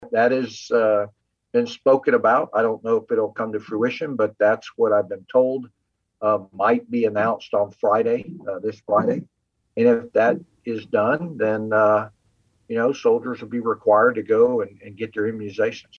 Gen. Perry Wiggins, who heads up Gov. Laura Kelly’s military council spoke about the potential mandate Thursday on KMAN’s In Focus.